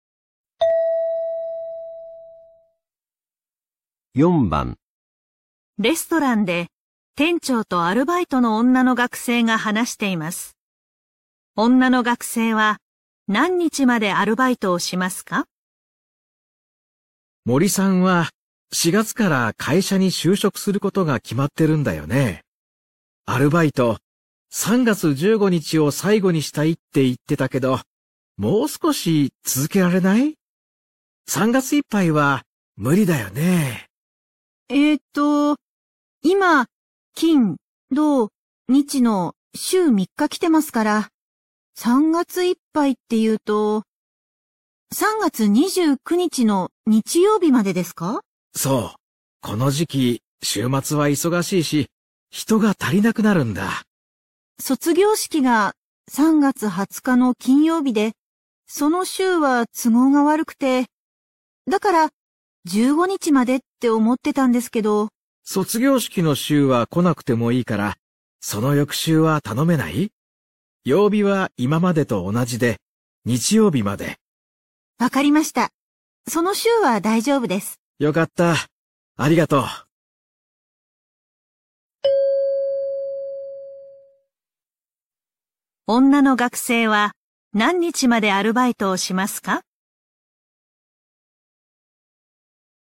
餐厅里，店长在和兼职的女学生说话。